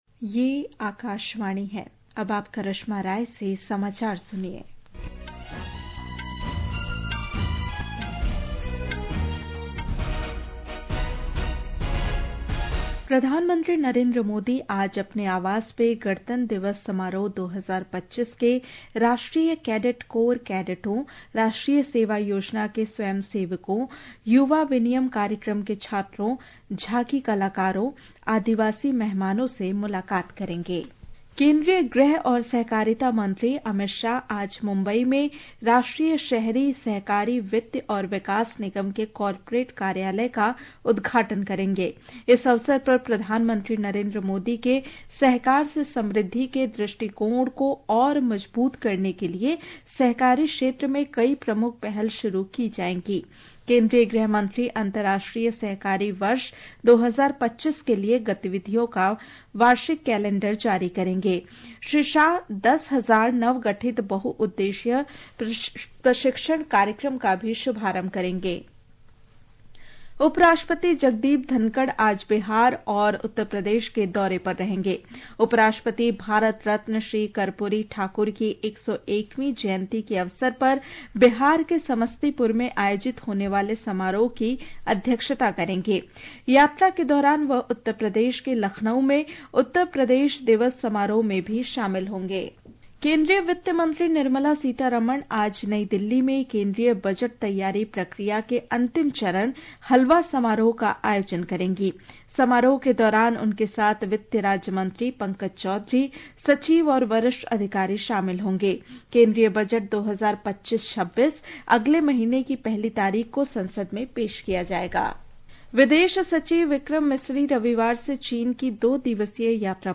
قومی بلیٹنز
प्रति घंटा समाचार